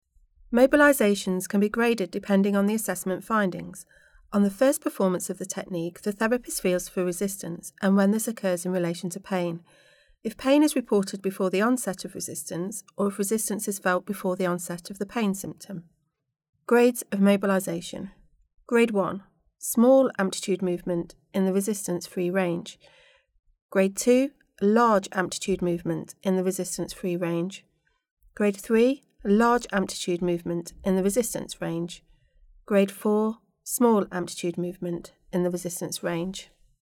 Read Aloud